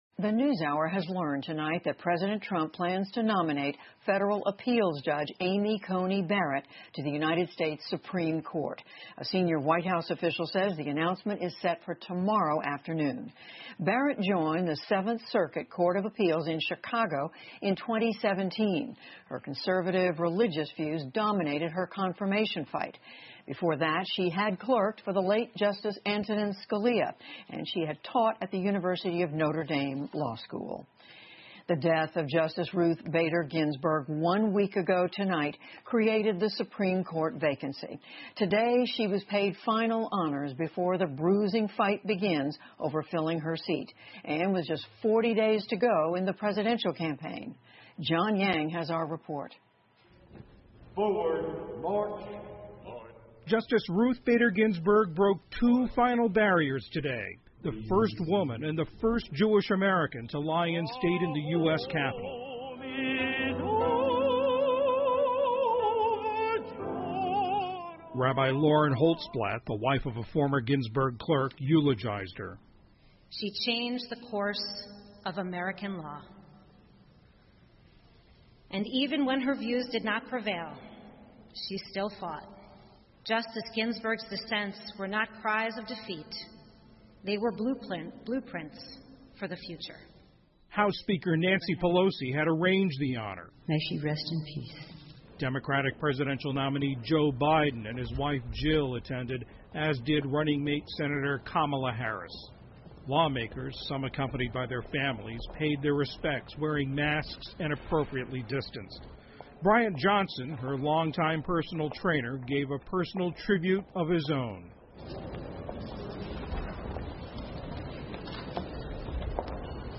PBS高端访谈:特朗普宣布大法官提名人选 听力文件下载—在线英语听力室